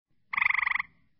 frog.mp3